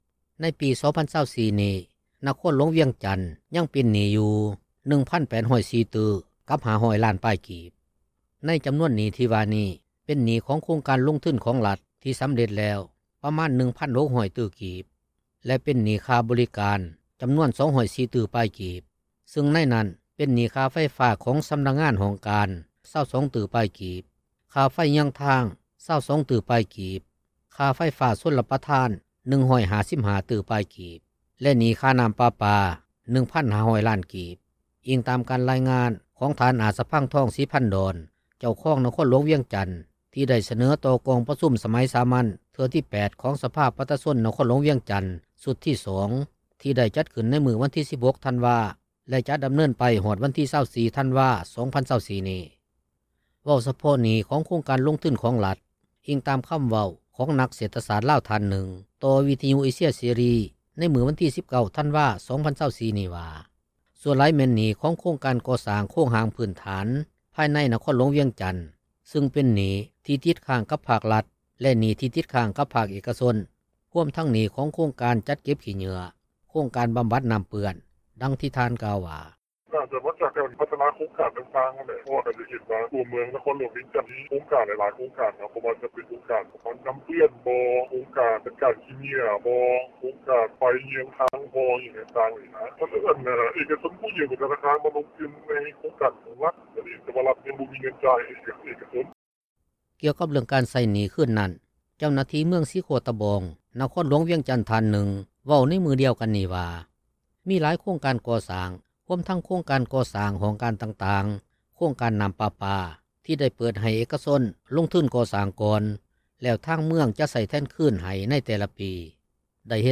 ນະຄອນຫຼວງວຽງຈັນ ຍັງເປັນໜີ້ຢູ່ 1,804,500,000,000 ປາຍກີບ — ຂ່າວລາວ ວິທຍຸເອເຊັຽເສຣີ ພາສາລາວ
ເວົ້າສະເພາະໜີ້ຂອງໂຄງການລົງທຶນຂອງລັດ ອີງຕາມຄໍາເວົ້າຂອງນັກເສດຖະສາດລາວທ່ານຫນຶ່ງ ຕໍ່ວິທຍຸເອເຊັຽເສຣີ ໃນມື້ວັນທີ 19 ທັນວາ 2024 ນີ້ວ່າ ສ່ວນຫຼາຍແມ່ນໜີ້ຂອງໂຄງການກໍ່ສ້າງໂຄງຮ່າງພື້ນຖານ ພາຍໃນນະຄອນຫຼວງວຽງຈັນ ຊຶ່ງເປັນໜີ້ ທີ່ຕິດຄ້າງກັບພາກລັດ ແລະໜີ້ທີ່ຕິດຄ້າງ ກັບພາກເອກກະຊົນ ຮວມທັງໜີ້ ຂອງໂຄງການຈັດເກັບຂີ້ເຫຍື້ອ, ໂຄງການບໍາບັດນໍ້າເປື້ອນດັ່ງທີ່ທ່ານກ່າວວ່າ: